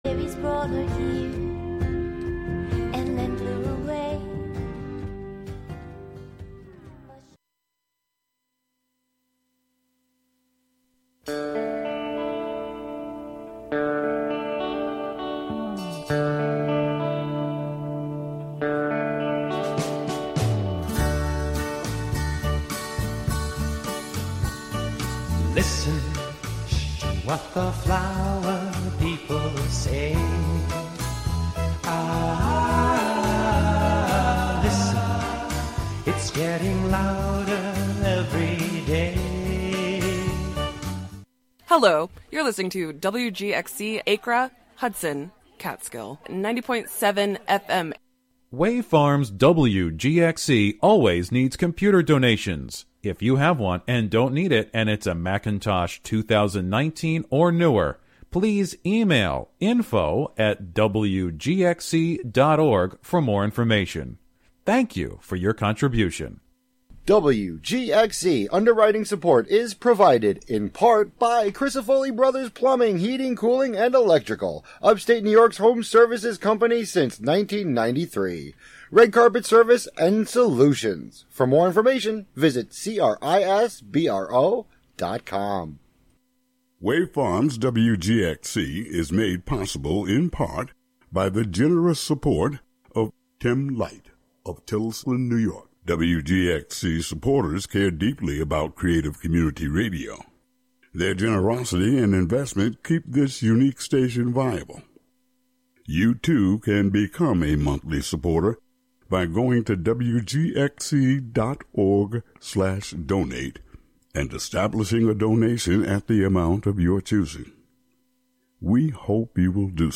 Gail Ann Dorsey (born November 20, 1962) is an American musician best known as a bassist, session musician, and vocalist.